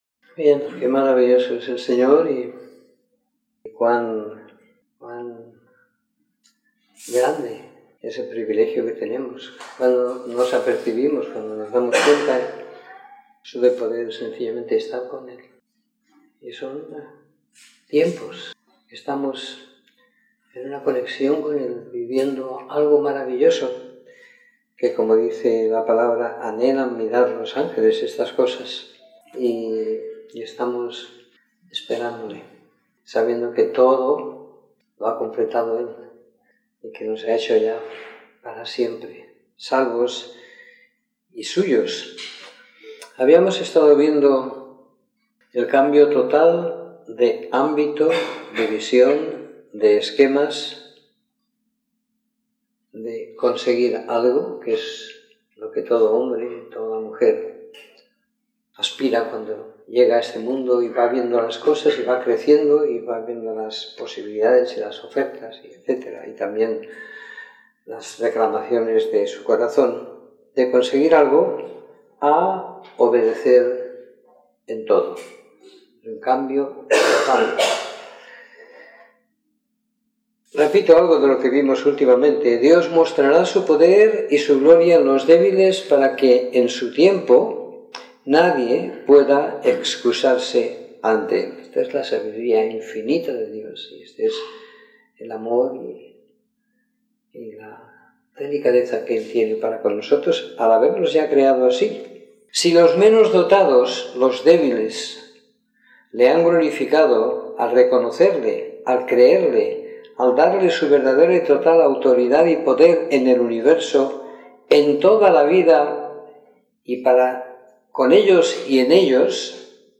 Domingo por la Tarde . 15 de Octubre de 2017